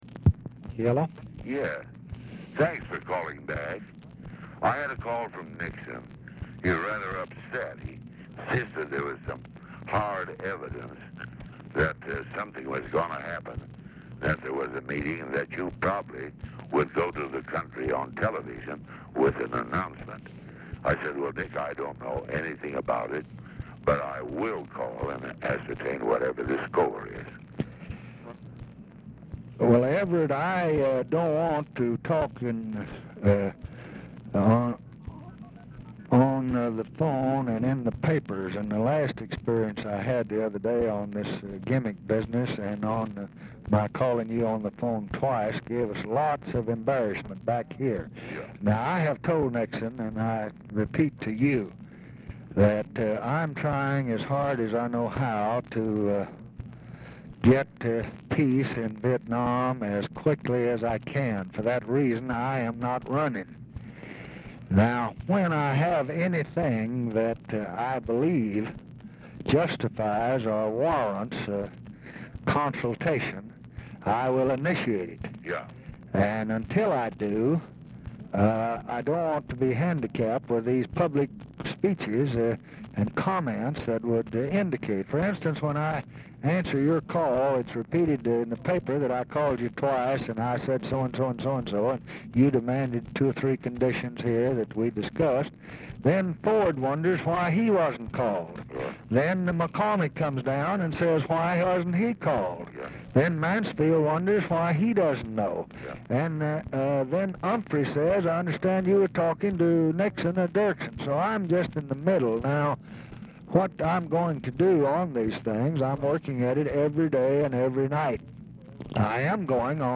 Telephone conversation # 13587, sound recording, LBJ and EVERETT DIRKSEN, 10/23/1968, 6:02PM | Discover LBJ
TELEPHONE OPERATOR SAYS "ARE YOU THERE?" AT END OF RECORDING
Format Dictation belt
Location Of Speaker 1 Oval Office or unknown location
Specific Item Type Telephone conversation